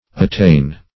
Attain \At*tain"\, n.